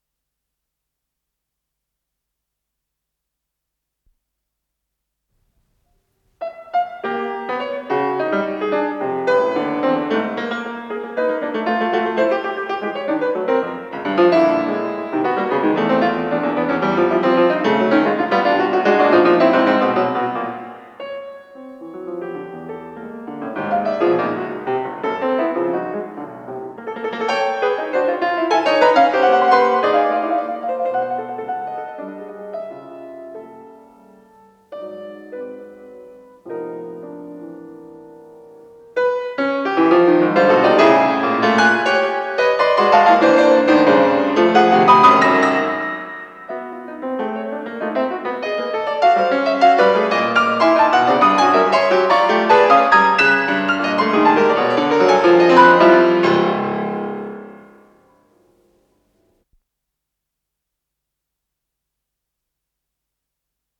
с профессиональной магнитной ленты
ИсполнителиАлексей Любимов - фортепиано